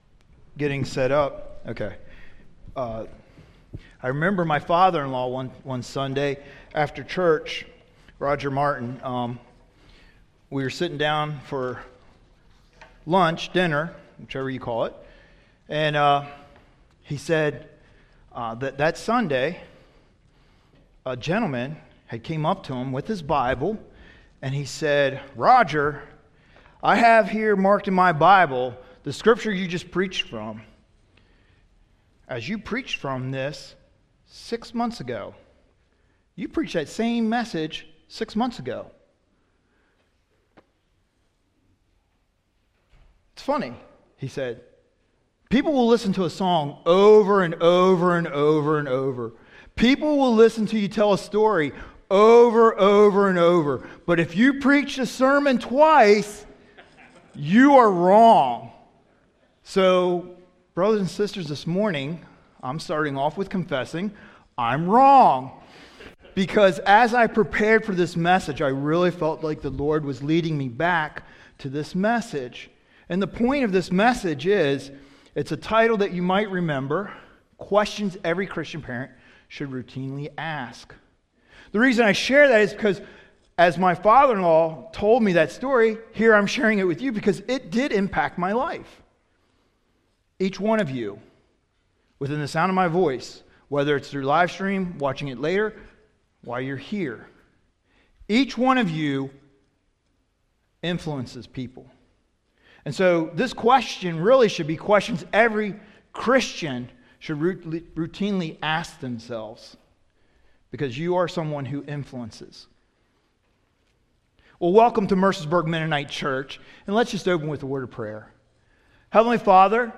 Baby Dedication - Mercersburg Mennonite Church